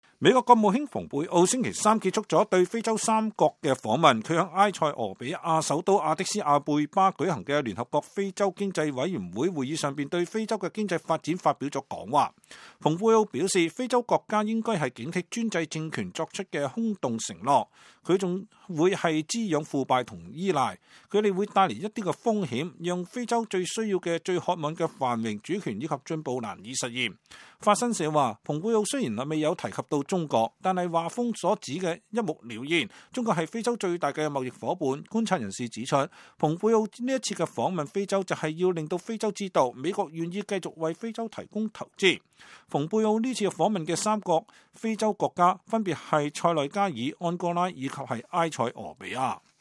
美國國務卿蓬佩奧在埃塞俄比亞首都亞的斯亞貝巴舉行的聯合國非洲經濟委員會會議上講話。